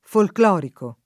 vai all'elenco alfabetico delle voci ingrandisci il carattere 100% rimpicciolisci il carattere stampa invia tramite posta elettronica codividi su Facebook folclorico [ folkl 0 riko ] (meglio che folklorico [id.]) agg.; pl. m. ‑ci